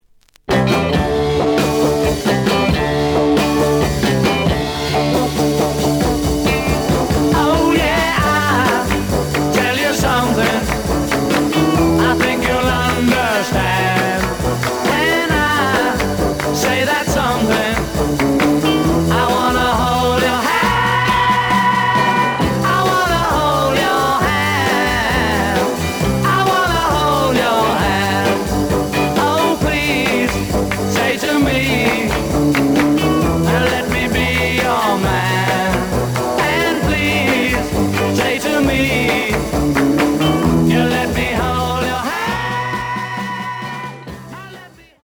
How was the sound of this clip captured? The audio sample is recorded from the actual item. Edge warp.